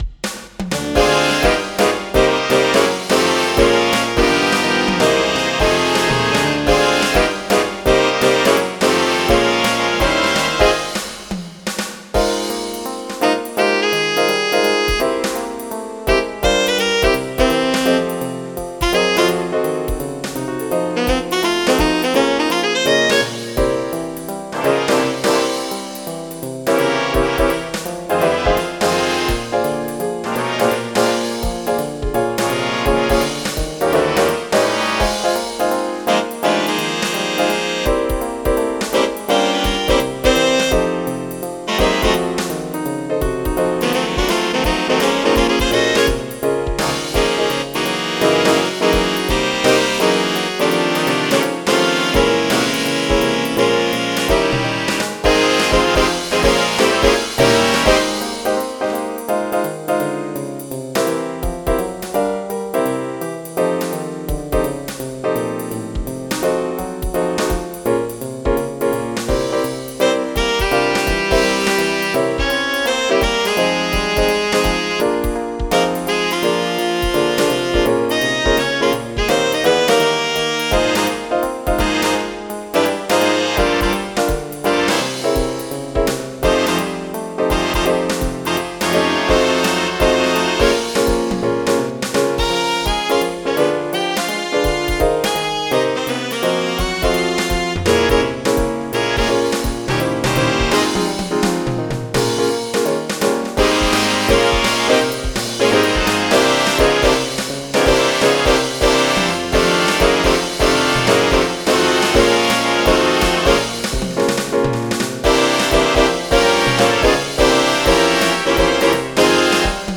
MIDI Music File
Syn Bass